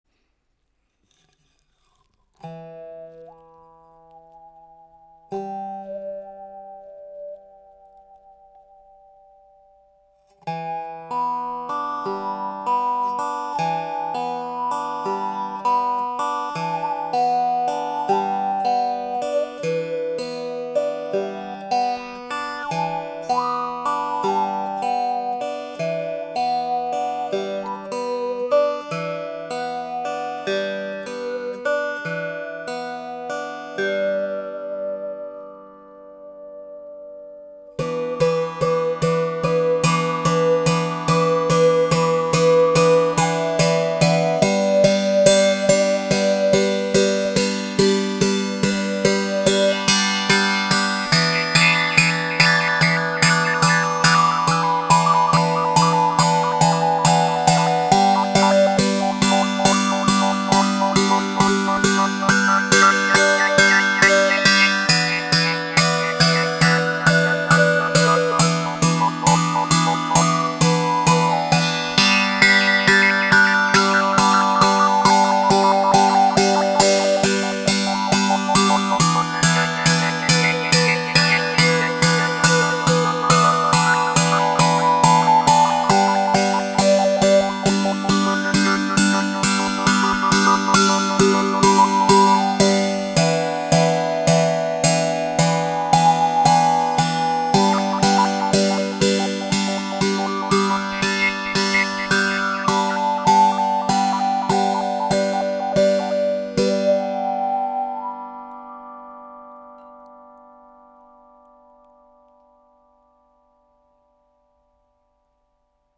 Le son est très chaud et attrayant…entrainant.
Les sons proposés ici sont réalisés sans effet.
La baguette sera utilisée pour percuter les cordes, l’une, l’autre ou toutes.